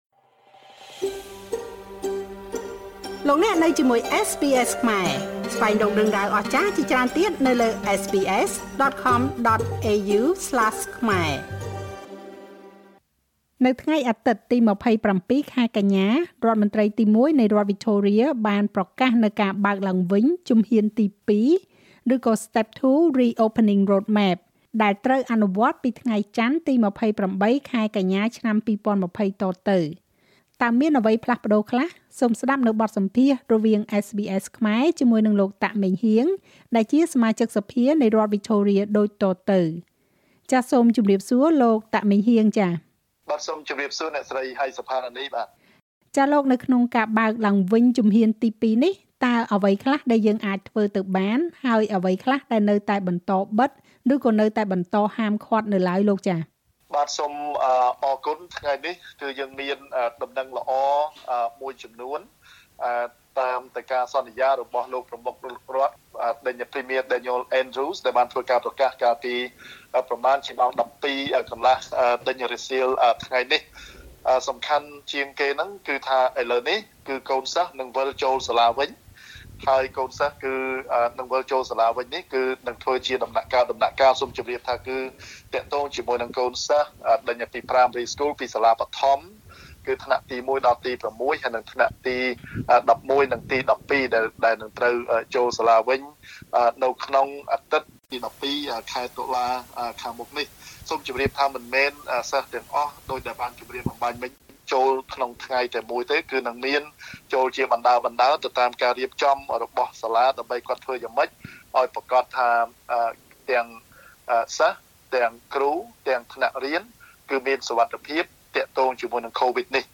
នៅថ្ងៃអាទិត្យទី២៧ ខែកញ្ញា រដ្ឋមន្រ្តីទីមួយនៃរដ្ឋវិចថូរៀបានប្រកាសនូវការបើកឡើងវិញជំហានទី២ (Step 2 re-opening roadmap) ដែលនឹងត្រូវអនុវត្តចាប់ពីថ្ងៃចន្ទ ទី២៨ ខែកញ្ញា ឆ្នាំ២០២០ តទៅ។ តើមានអ្វីផ្លាស់ប្តូរខ្លះ? សូមស្តាប់បទសម្ភាសន៍រវាង SBS ខ្មែរ ជាមួយនឹងលោក តាក ម៉េងហ៊ាង សមាជិកសភានៃរដ្ឋវិចថូរៀដូចតទៅ។